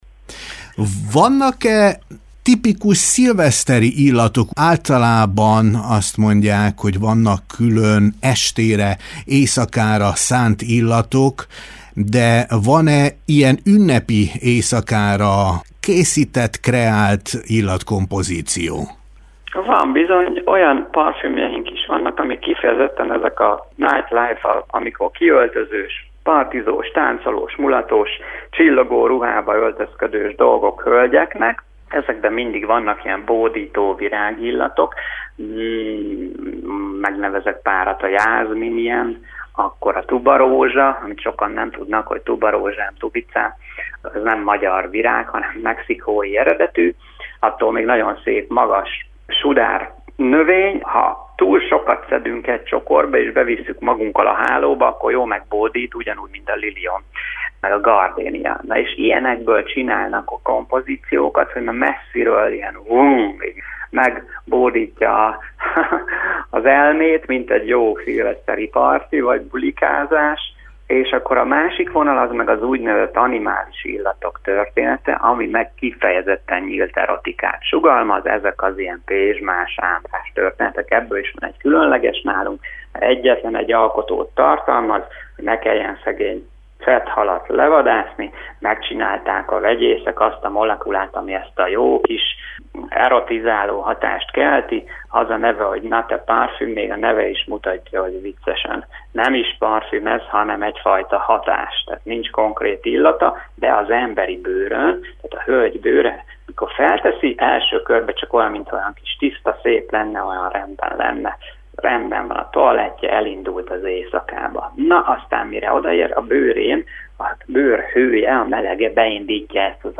Kiöltözős, partizós jellegű illatok is vannak, ezek használhatók például egy szilveszteri mulatságban is. A következő beszélgetésből az is kiderül, hogy milyen illatot visel a parfümkreátor az ünnepen, illetve, hogy milyen illatemlékei vannak gyerekkorából.